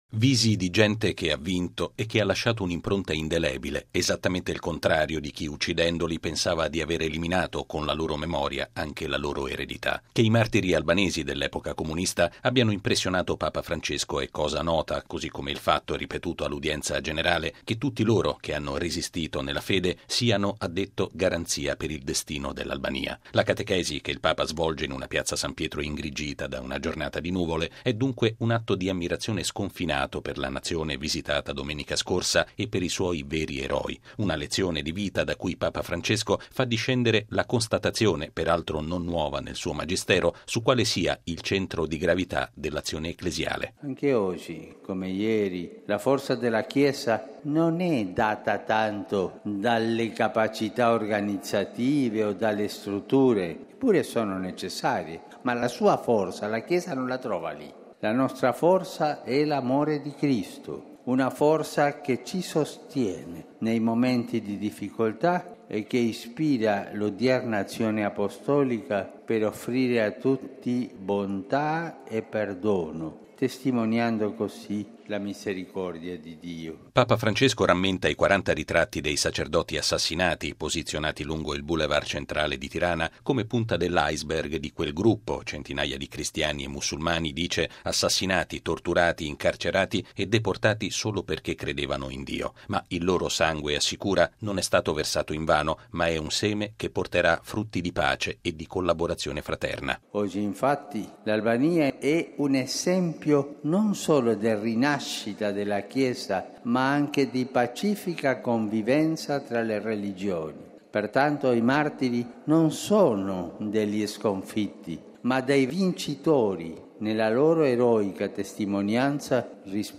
Lo ha affermato Papa Francesco all’udienza generale in Piazza S. Pietro, tenuta davanti a circa 30 mila persone.